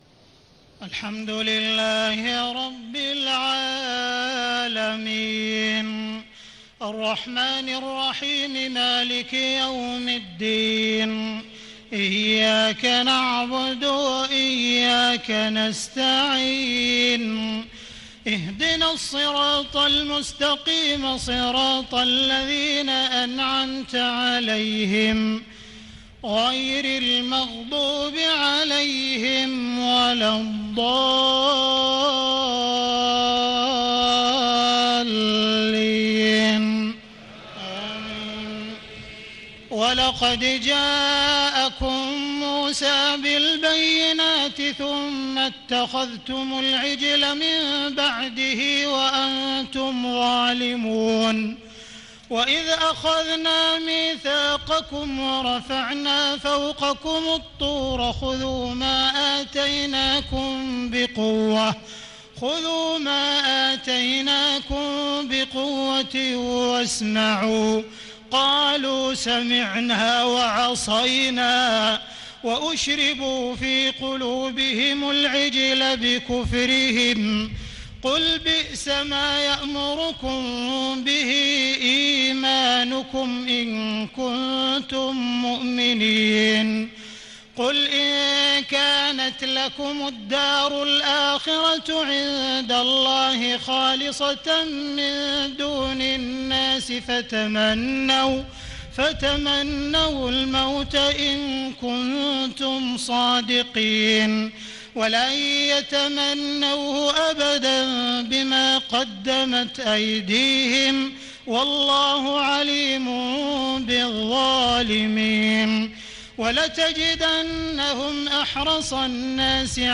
تهجد ليلة 21 رمضان 1436هـ من سورة البقرة (92-141) Tahajjud 21 st night Ramadan 1436H from Surah Al-Baqara > تراويح الحرم المكي عام 1436 🕋 > التراويح - تلاوات الحرمين